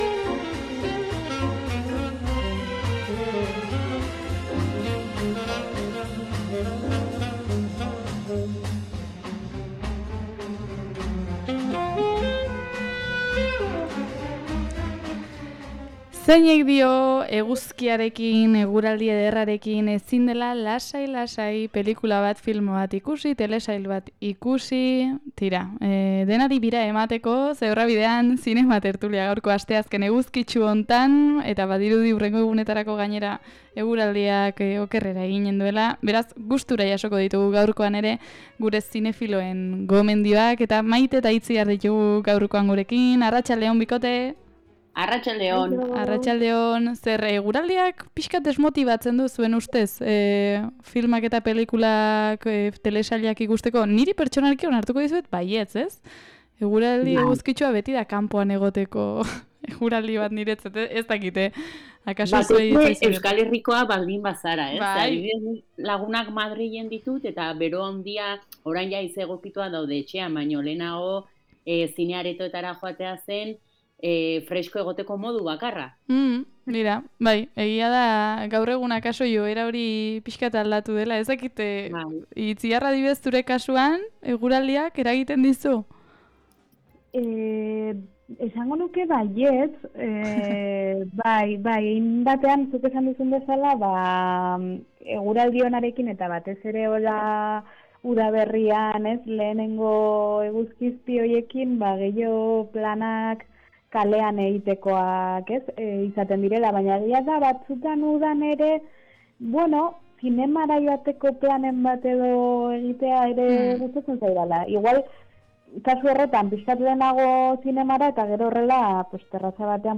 Sentsazio arraroarekin gelditzeko filmak · Zine tertulia 04.30